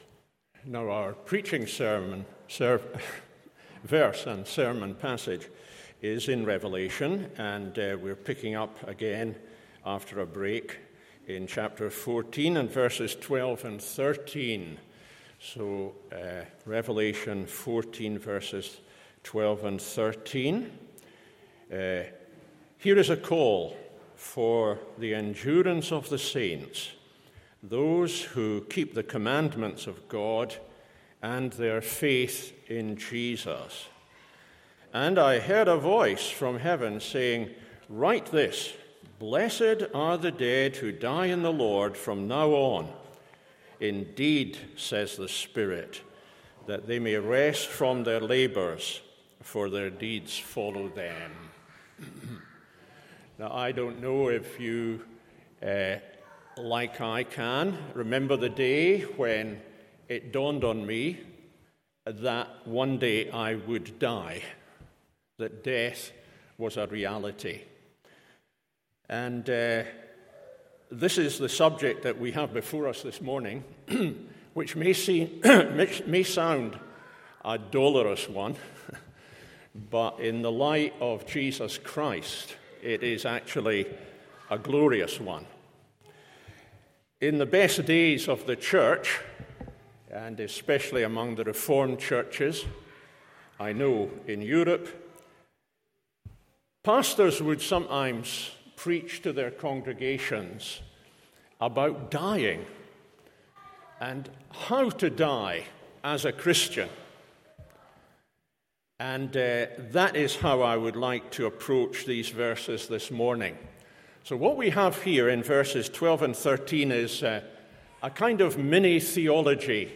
Morning Service Revelation 14:12-13…